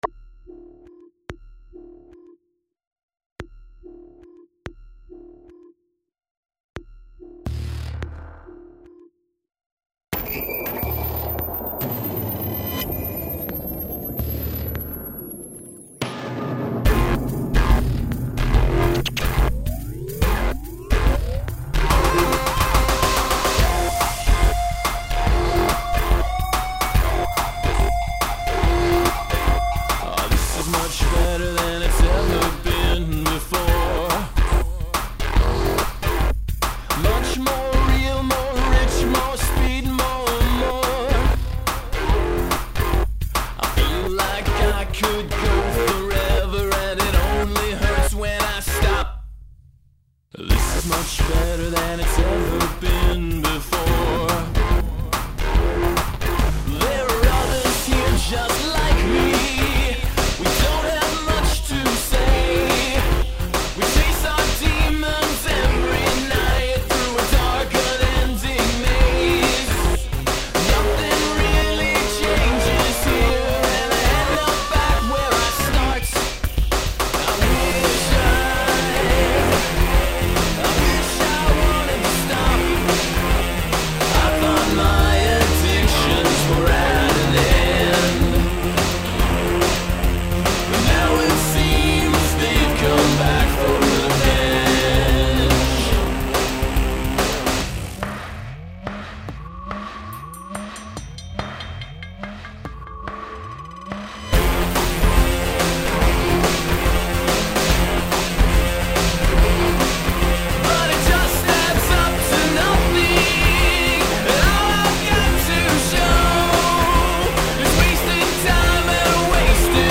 I also wanted to do something that was sort of swing-y and in 6/8.
Vocals are too loud and probably have too many words.